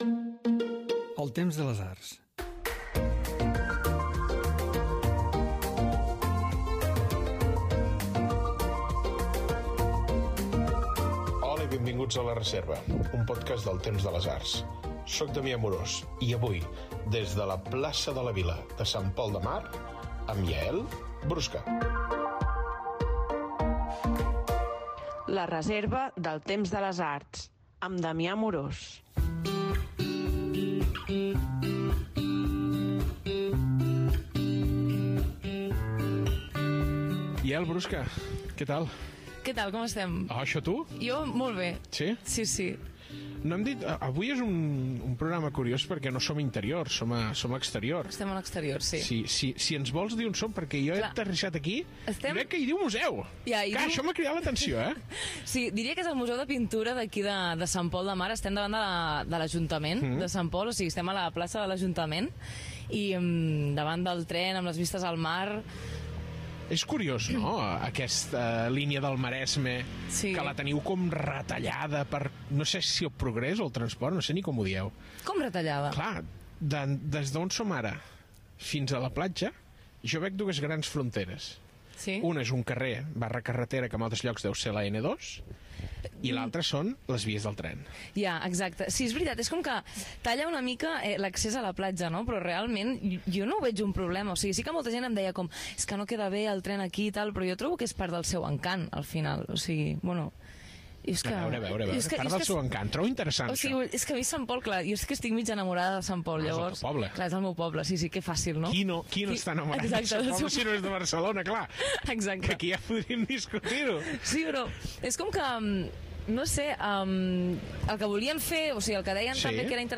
entrevista feta a Sant Pol de Mar